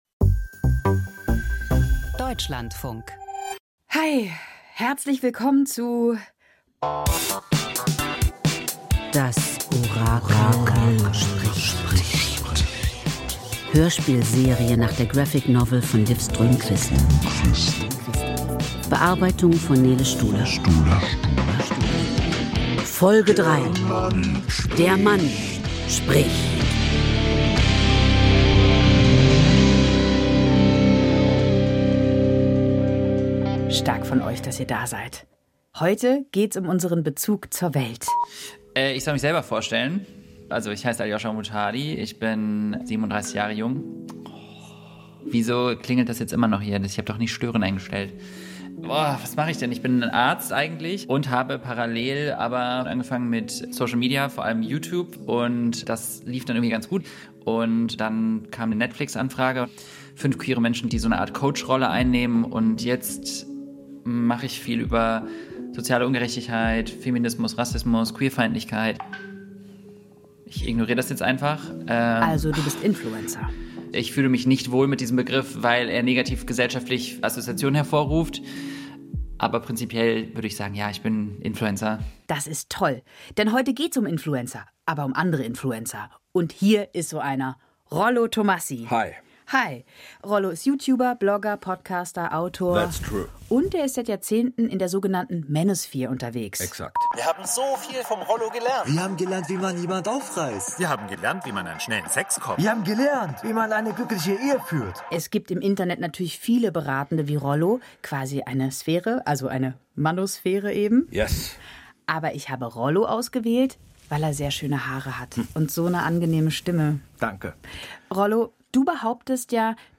Serie Hörspiel Das Orakel spricht (3/7) Der Mann spricht 22:13 Minuten Manfluencer betrachten die Welt aus einem ganz spezifischen Blickwinkel.